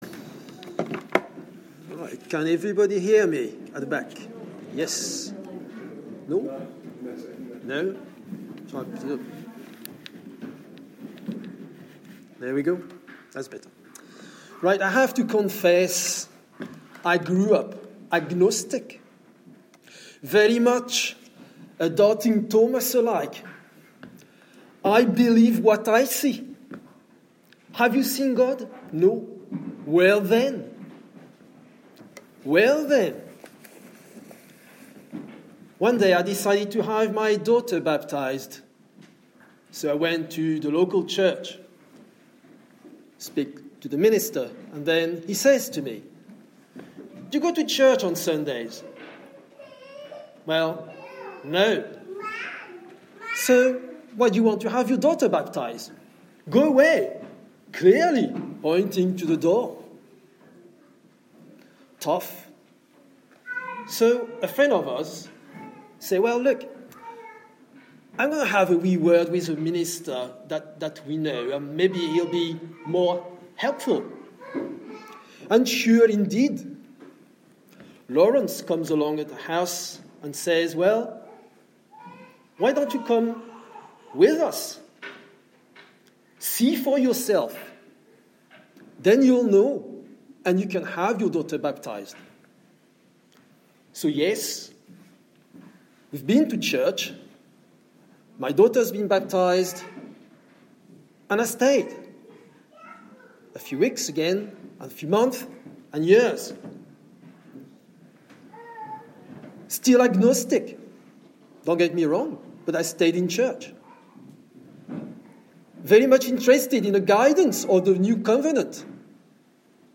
Two Testimonies- Easter Sunday 2018